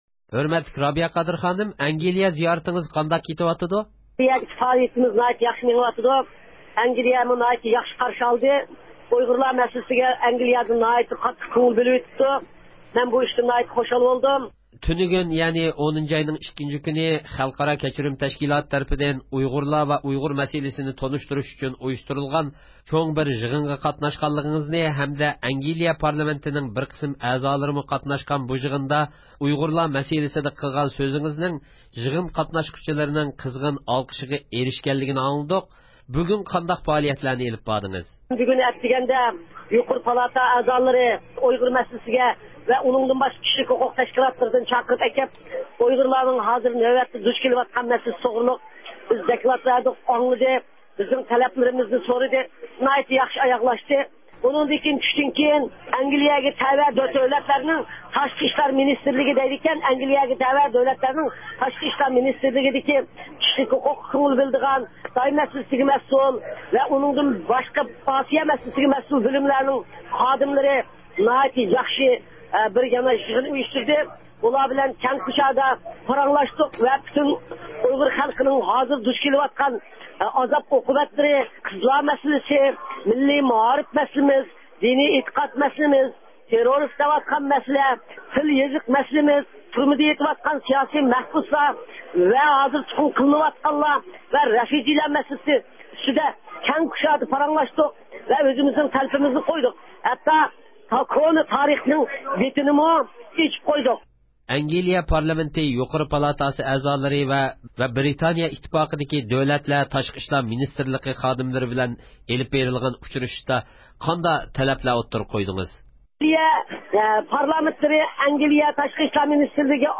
رابىيە قادىر خانىم بىلەن ئۆتكۈزگەن سۆھبىتىنىڭ تەپسىلاتىنى دىققىتىڭلارغا سۇنىمىز.